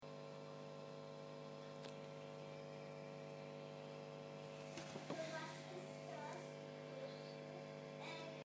Das Brummen bleibt im Clean-Kanal auch wenn ich V1-V3 gezogen habe.
angehängt noch das Brummen, wie vor Jahren schonmal aufgenommen
Anhänge Verstärker Brummen.mp3 72,5 KB